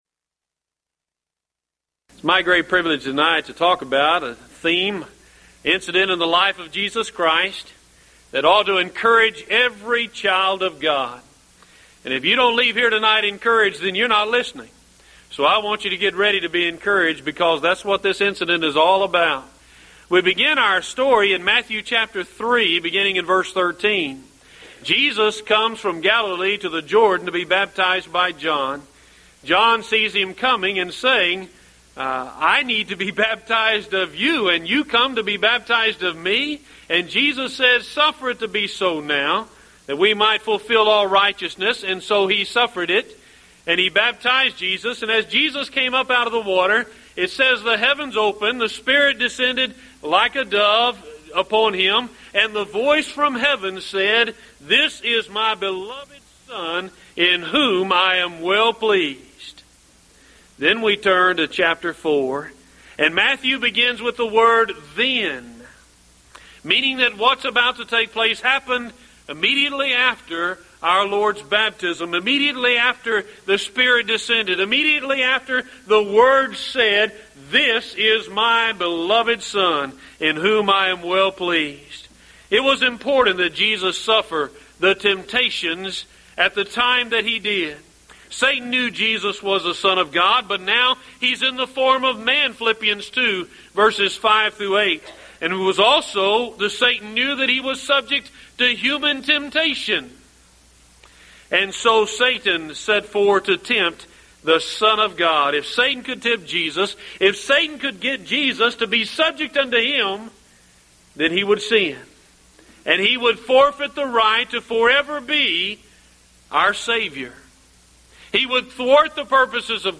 Series: Mid-West Lectures